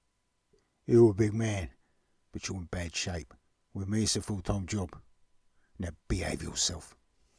Cockney Gangster